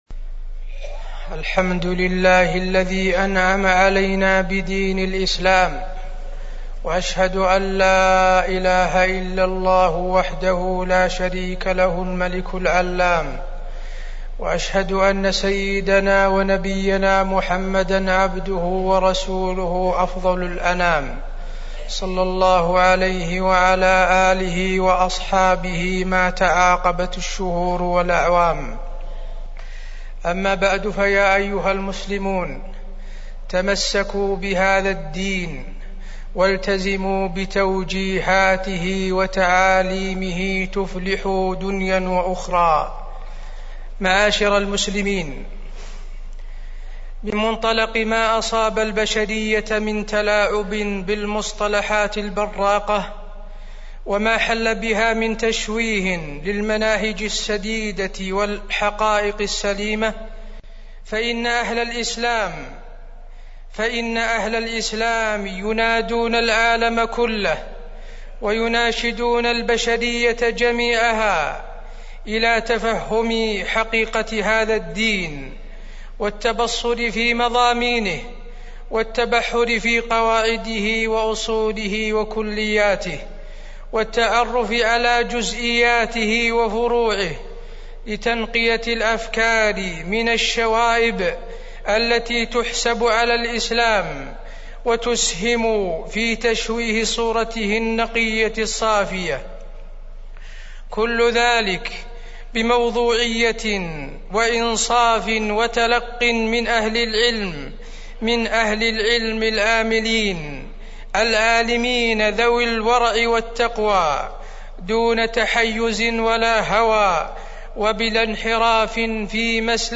تاريخ النشر ١٩ ربيع الثاني ١٤٢٦ هـ المكان: المسجد النبوي الشيخ: فضيلة الشيخ د. حسين بن عبدالعزيز آل الشيخ فضيلة الشيخ د. حسين بن عبدالعزيز آل الشيخ محاسن الإسلام